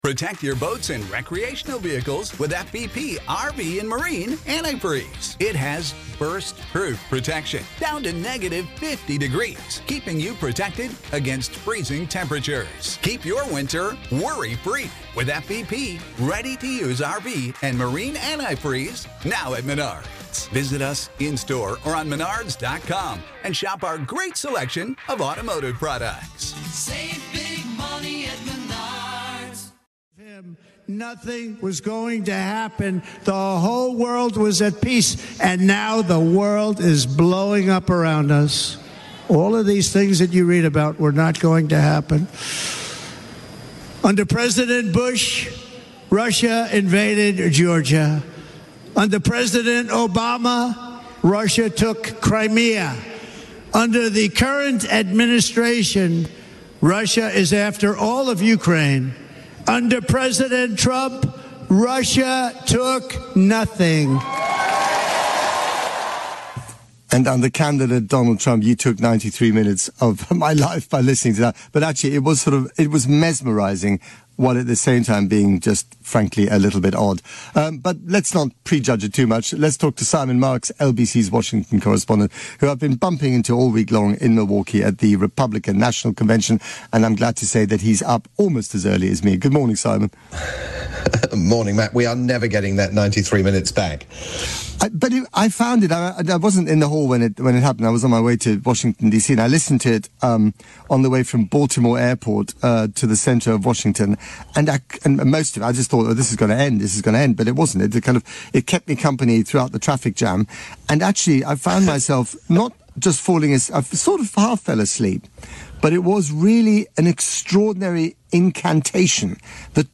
live chat with Matt Frei on the UK's LBC.